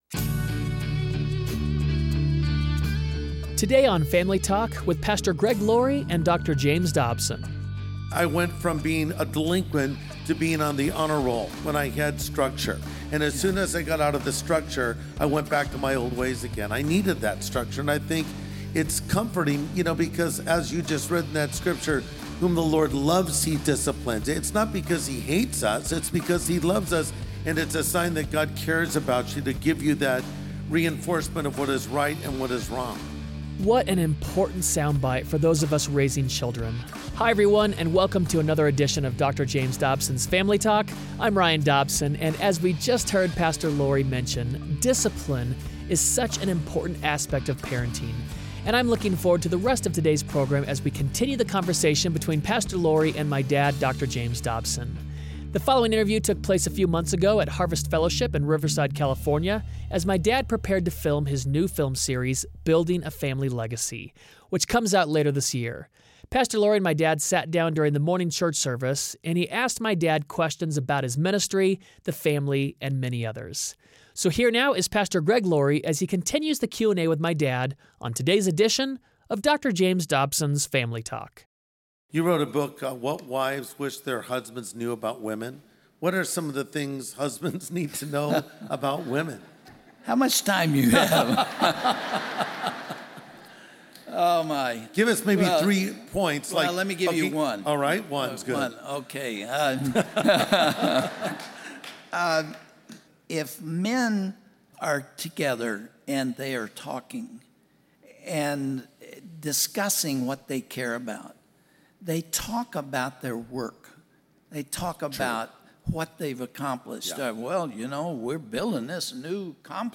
Have you ever wanted to sit down with Dr. Dobson and ask him questions about his life and ministry? Pastor Greg Laurie sits down with Dr. Dobson for a Q&A.